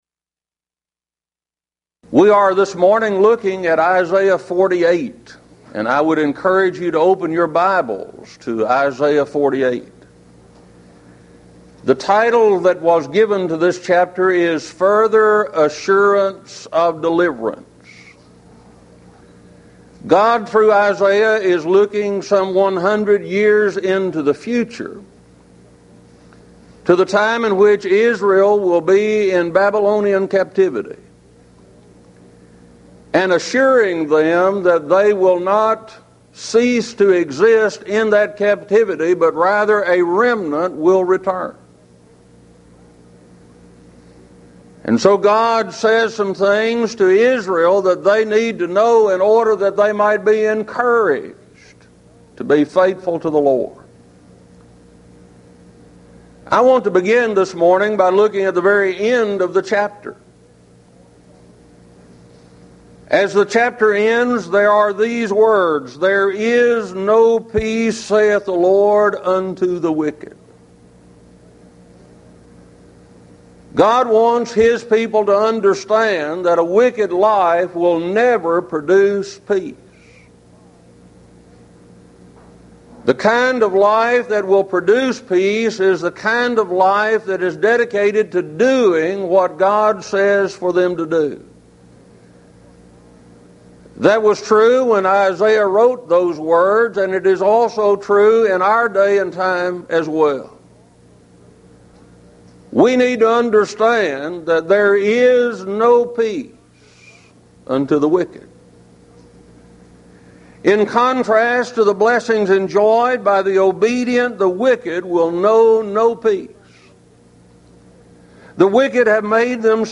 Series: Houston College of the Bible Lectures Event: 1996 HCB Lectures Theme/Title: The Book Of Isaiah - Part II